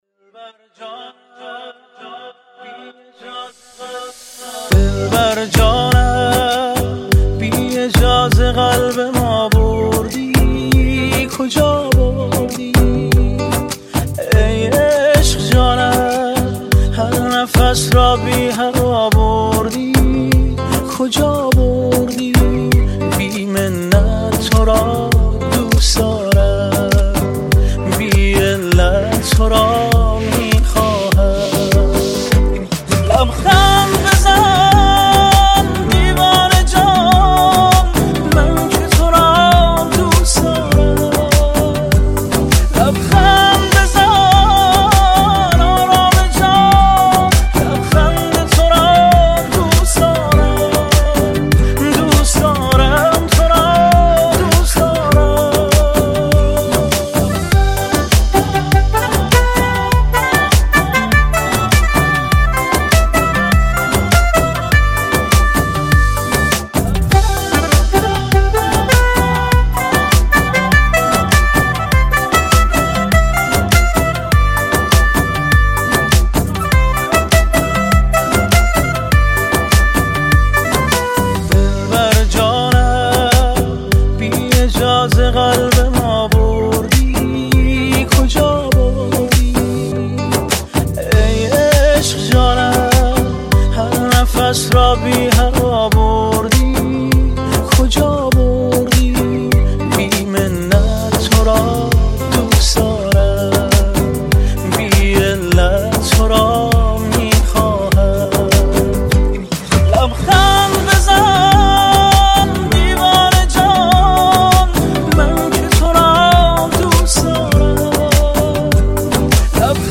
خواننده پاپ